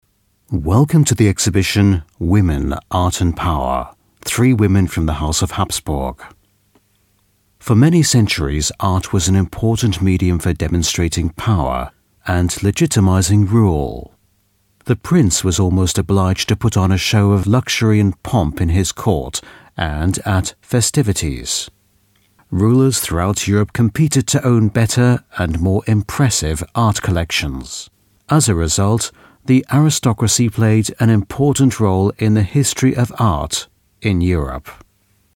Native Speaker
Audioguides